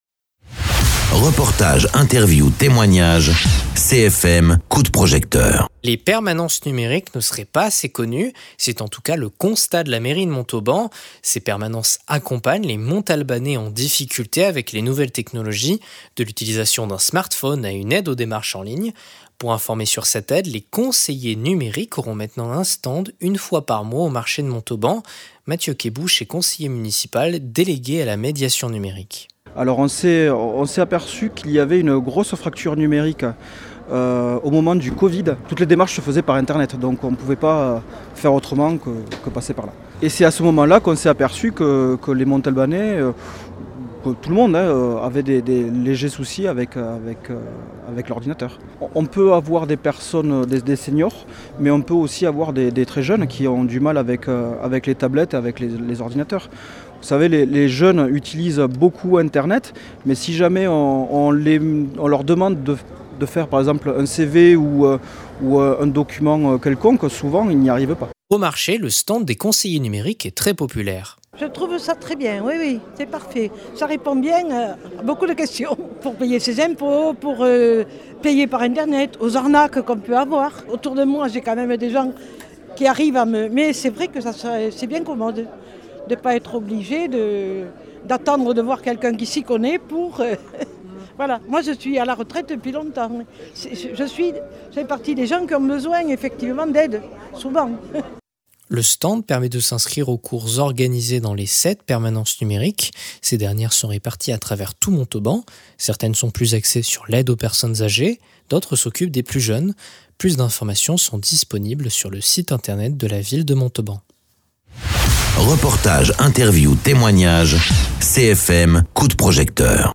Interviews
Invité(s) : Mathieu Kébouche, conseiller municipal délégué à la médiation numérique.